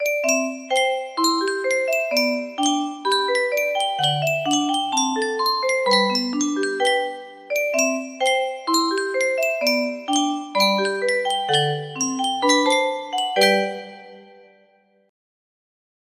Clone of Sankyo Music Box - Aloha Oe 9E music box melody